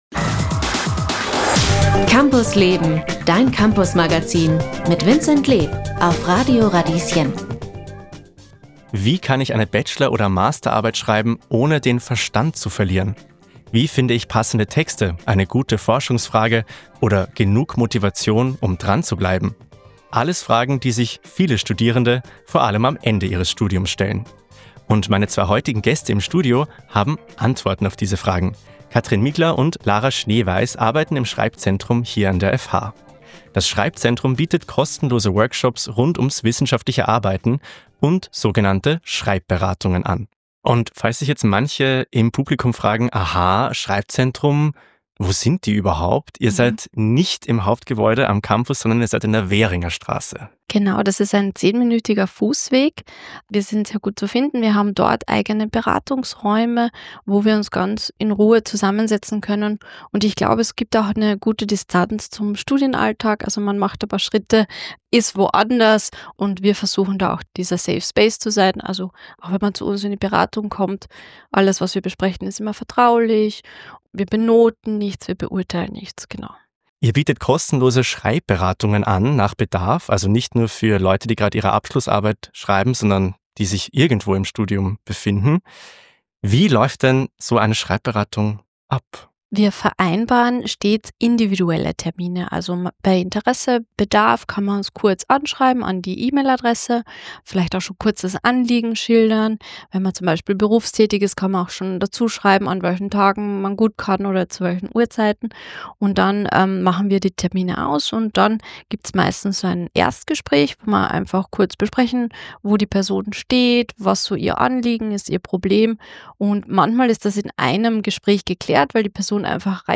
Dieser Podcast ist ein Ausschnitt aus der Campus Leben-Radiosendung vom 19. März 2025.